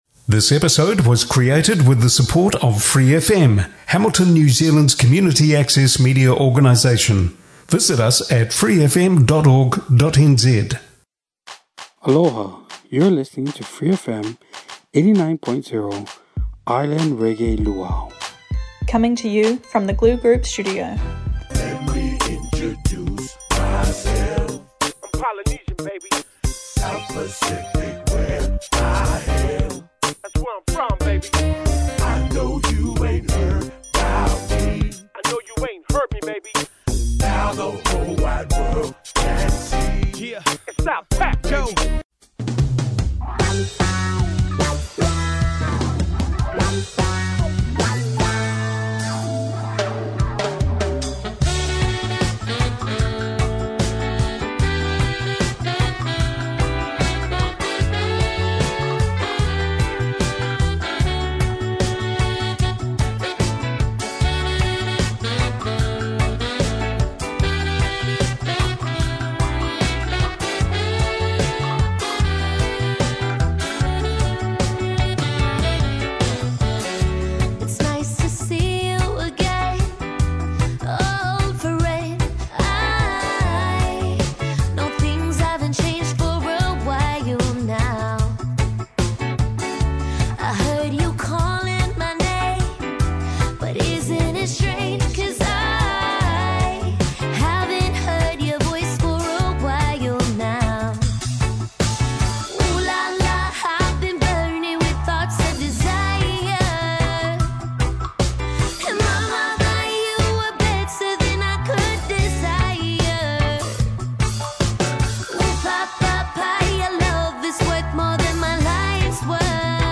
A feast of reggae, delivered in uniquely Hawaiian style.